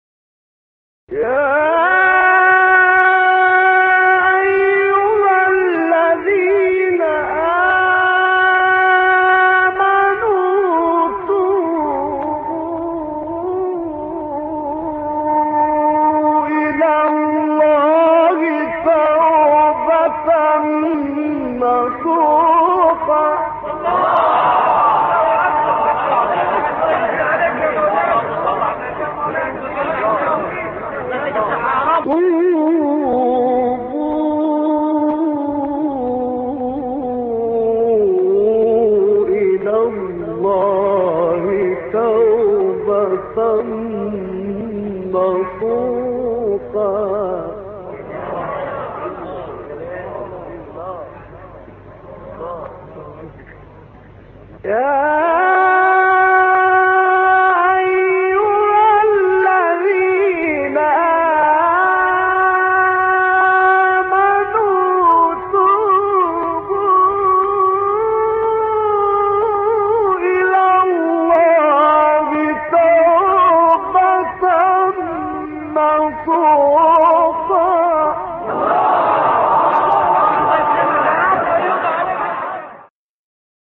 استاد «محمد عبدالعزیز حصان» یکی از مشاهیر تلاوت قرآن کریم است و به لحاظ برجستگی در ادای نغمات و دقت در ظرافت‌های وقف و ابتداء او را «ملک الوقف و الإبتدا و التنغیم» یعنی استاد الوقف و الابتداء و تلوین النغمی لقب داده‌اند.
در ادامه ۵ قطعه کوتاه از زیباترین تلاوت‌های استاد محمد عبدالعزیز حصان ارائه می‌شود.